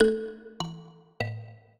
mbira
minuet7-10.wav